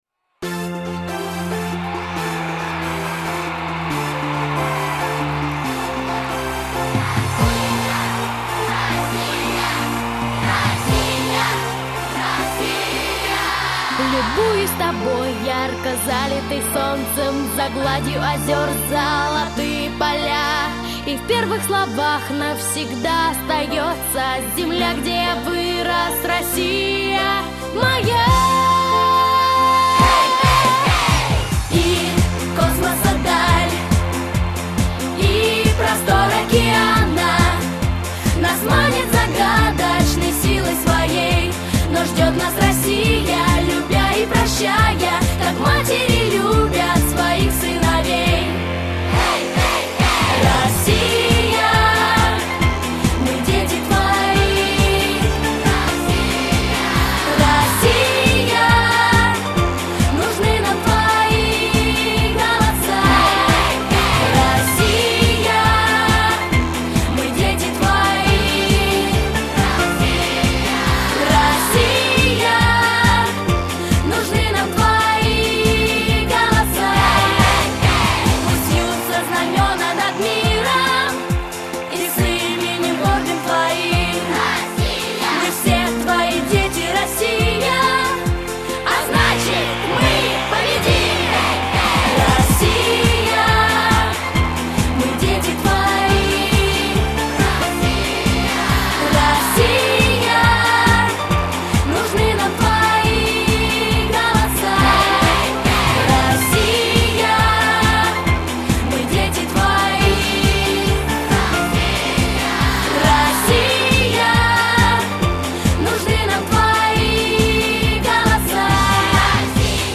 МУЗЫКА (